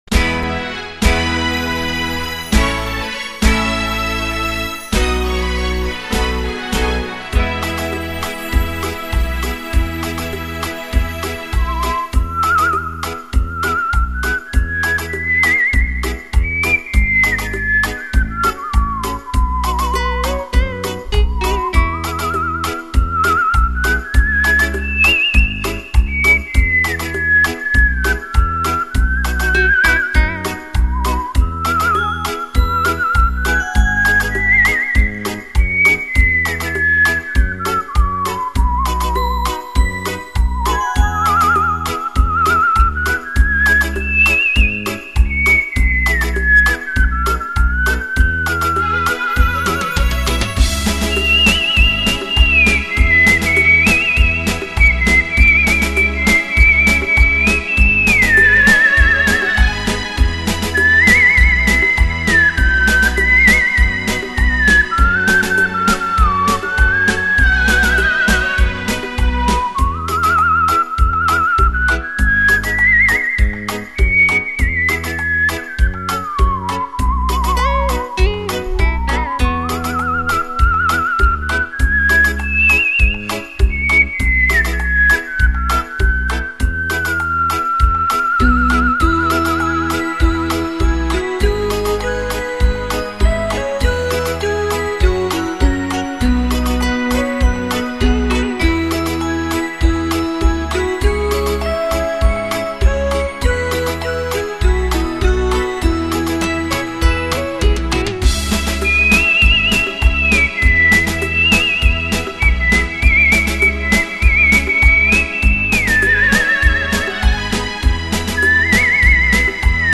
★音乐声响起，全身自然放松下来，
嘹亮清脆口哨令人倾倒
伴有舞曲节奏。
好，有口哨，太好了